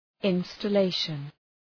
Shkrimi fonetik {,ınstə’leıʃən}